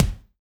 BEAT KICK 01.WAV